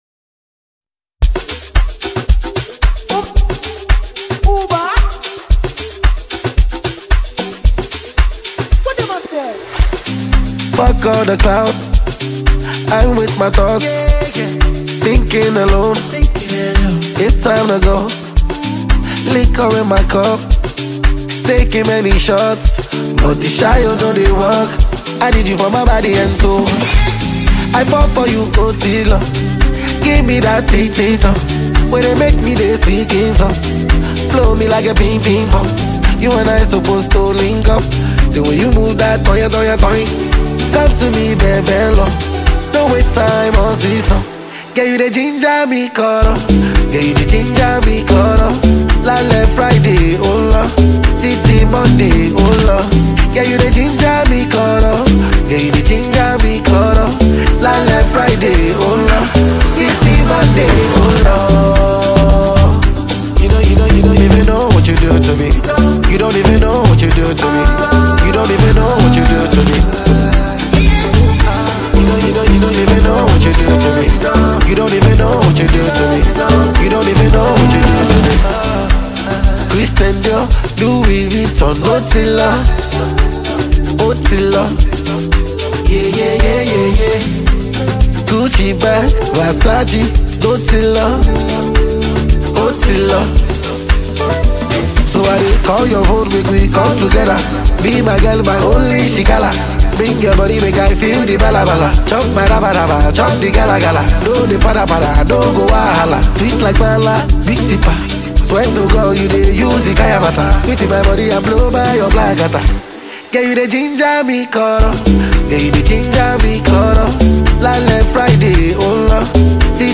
Get this energizing song